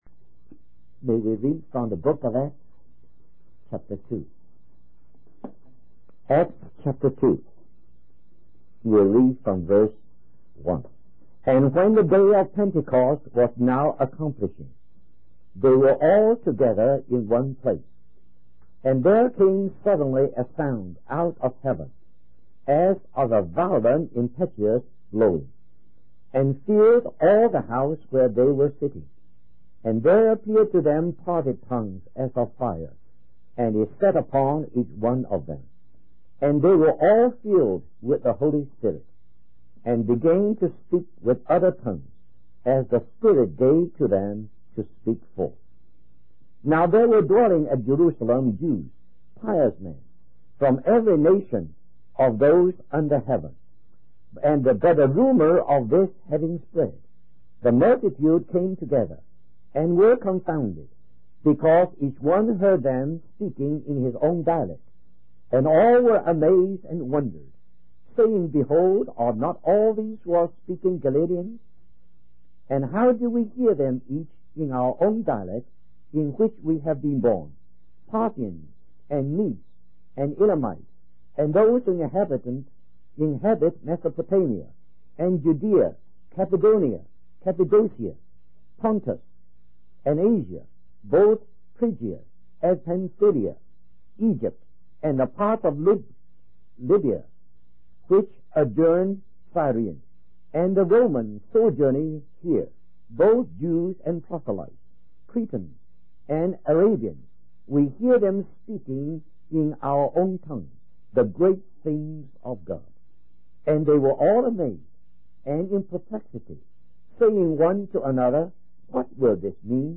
In this sermon, the speaker emphasizes the importance of understanding the significance of the body of Christ. He explains that while Jesus physically preached and performed miracles during his time on earth, he continues to do so through his church today. The speaker highlights the event of Pentecost, where 120 believers were baptized into one body by the Holy Spirit.